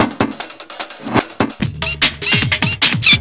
FXBEAT07-L.wav